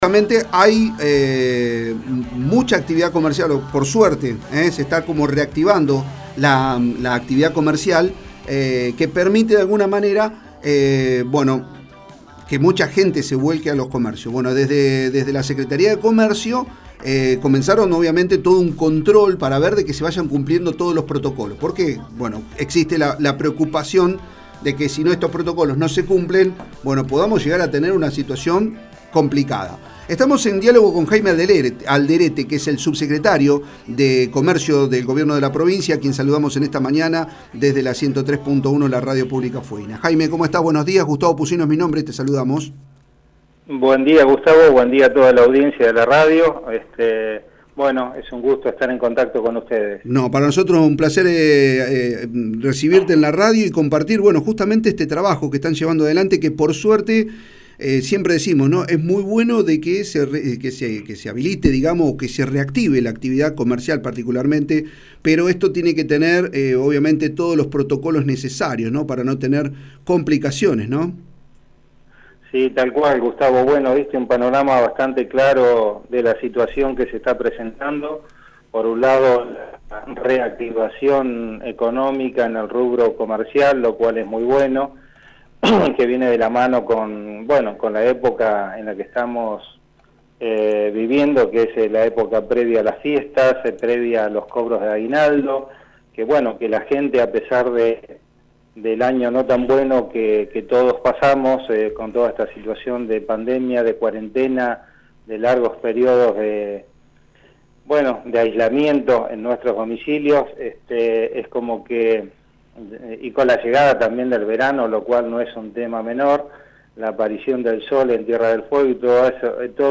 El subsecretario de comercio, Jaime Alderete, habló en el programa “La Otra Mañana”, de la 103.1, la radio pública fueguina, sobre la tarea que desarrollan en los locales.